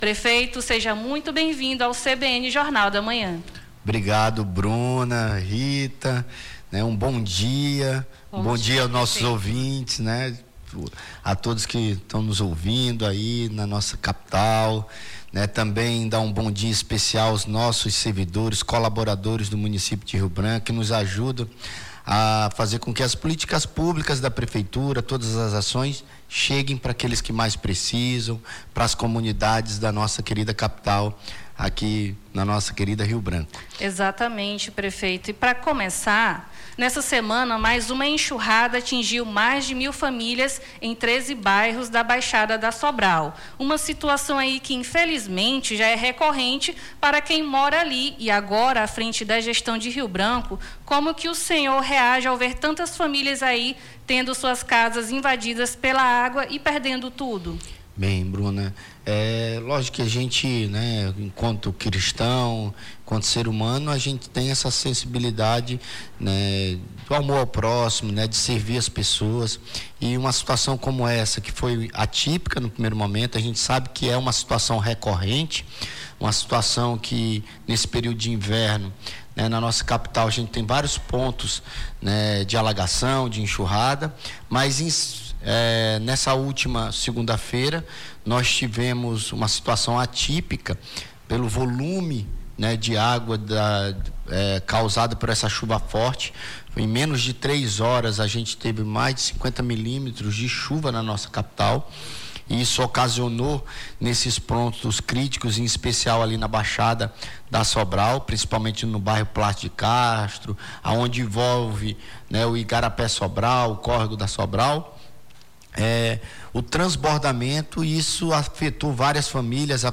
Na manhã desta quinta-feira, 16, conversamos com o prefeito de Rio Branco, Alysson Bestene (PP), sobre a sua nova gestão e prioridades na cidade.